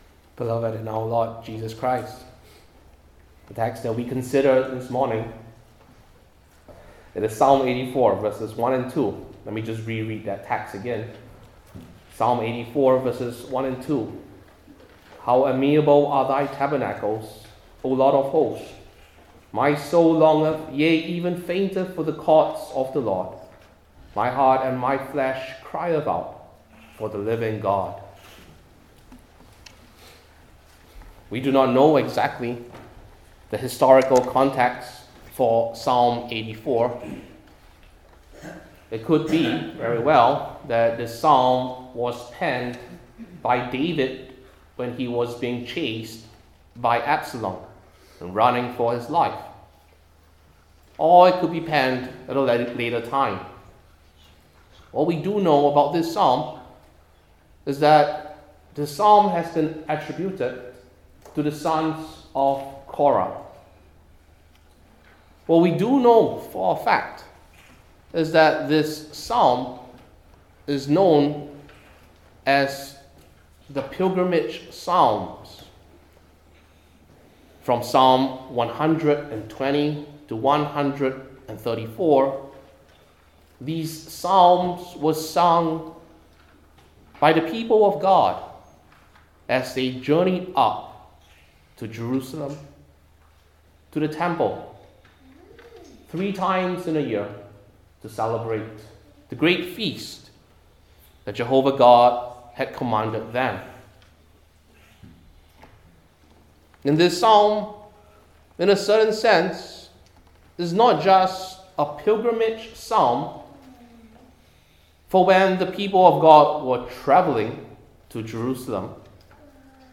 Old Testament Individual Sermons I. The Expression II.